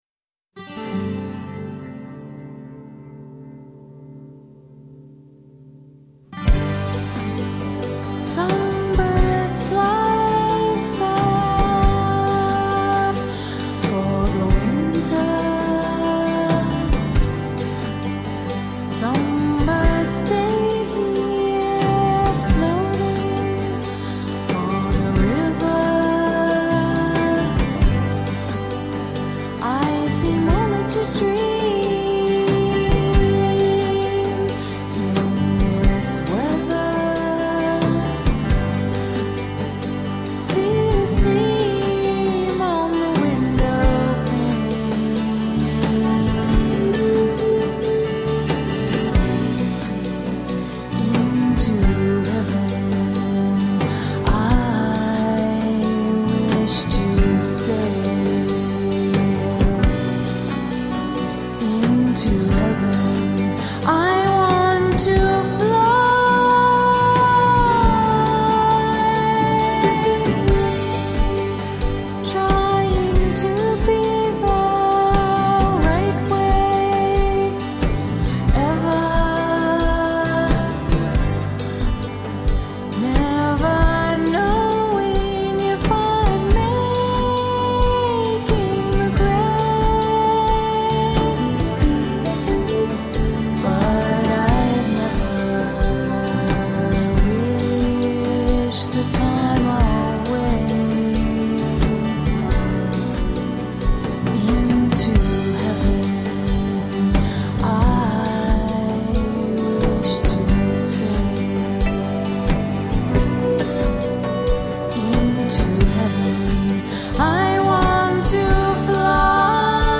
Gossamer folk-rock.